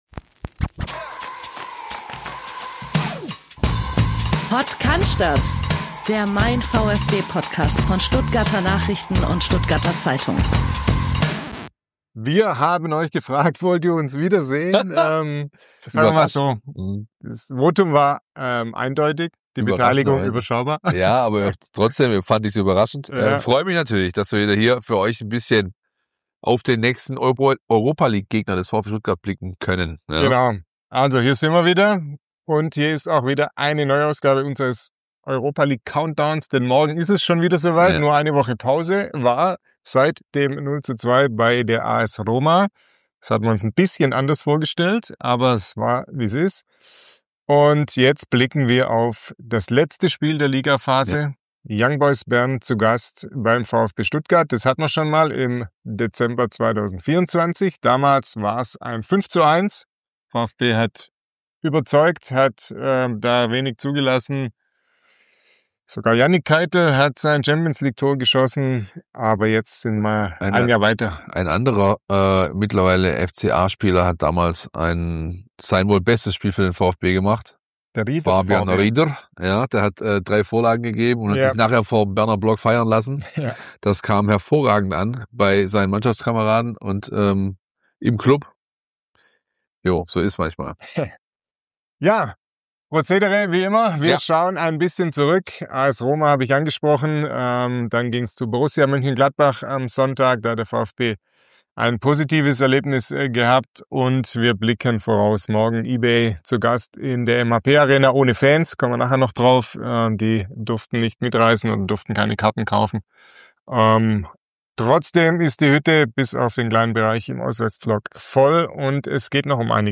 Es handelt sich um ein Audio-Re-Live des YouTube-Streams von MeinVfB.